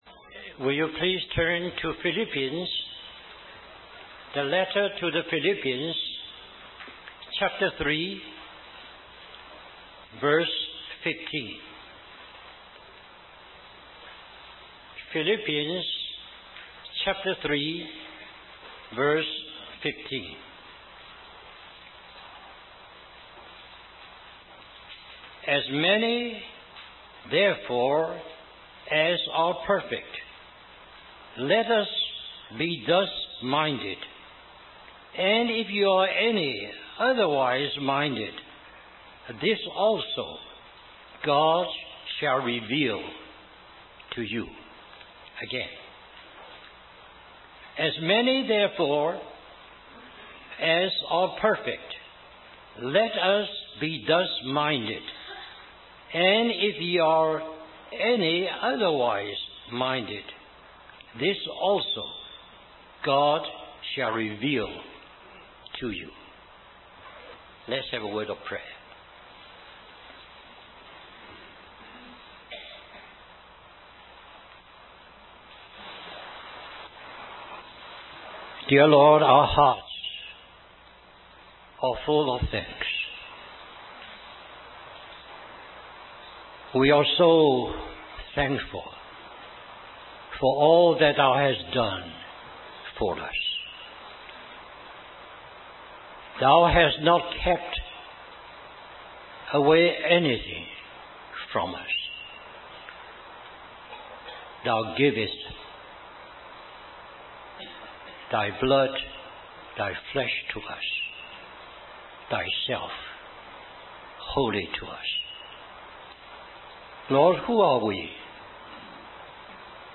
In this sermon, the speaker emphasizes the importance of renewing our minds in order to walk in the way of spiritual perfection. He explains that before encountering God, our minds are focused on worldly things, but through surrendering to God, our minds undergo a transformation.